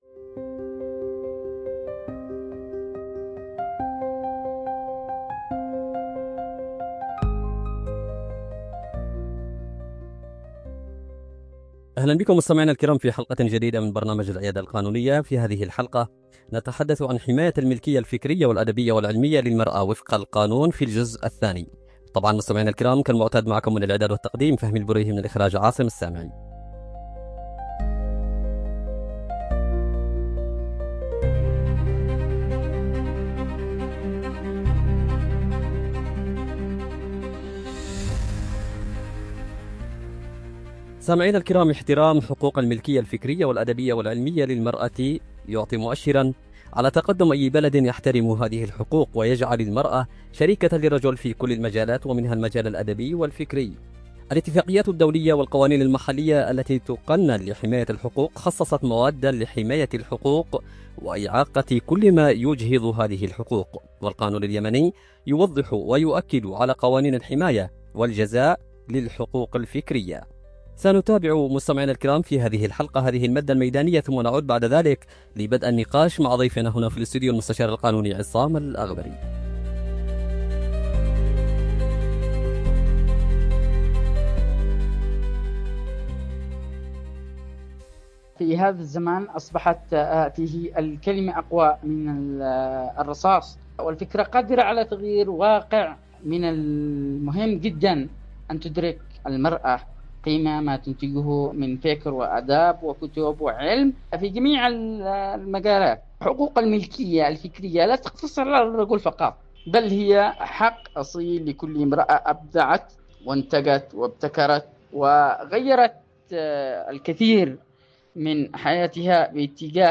في نقاش معمّق حول مدى كفاية التشريعات اليمنية لحماية إبداع المرأة
عبر إذاعة رمز